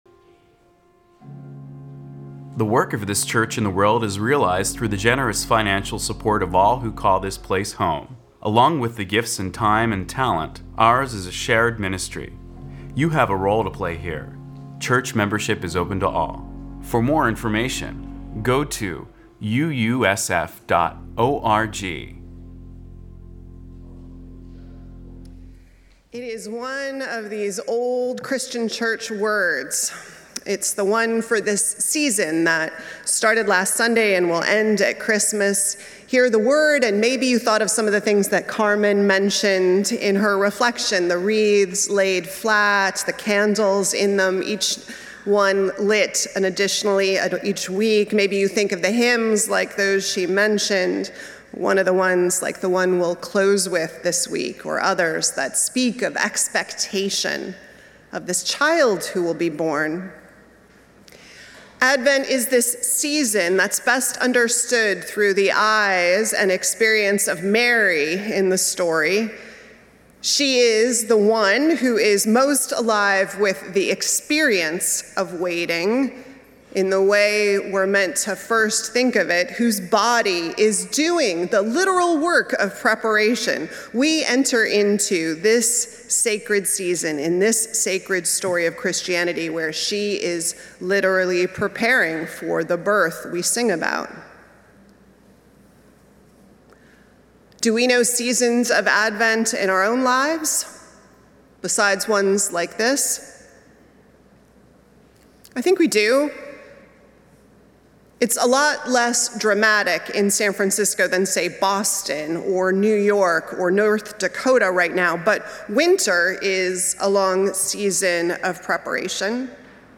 First Unitarian Universalist Society of San Francisco Sunday worship service.